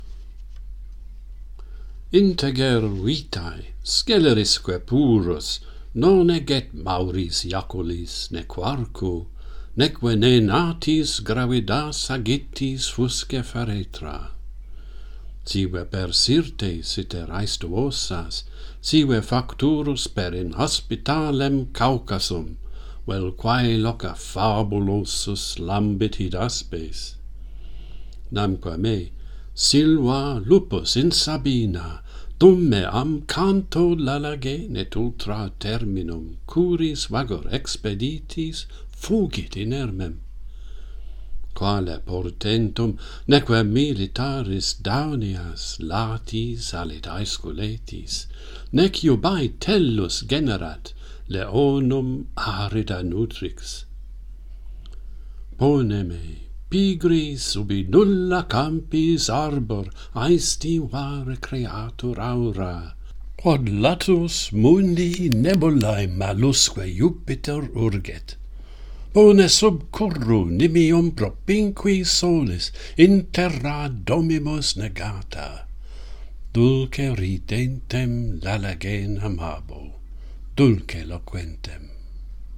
Horace, the wolf and the upright life - Pantheon Poets | Latin Poetry Recited and Translated
Metre: Sapphic